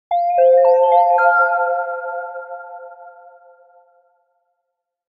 Futuristic Notification Sound Effect – Modern Digital UI Alert
Modern digital UI alert. Futuristic tech sound effect with a clean digital tone.
Soft, melodic, and minimal.
Futuristic-notification-sound-effect-modern-digital-UI-alert.mp3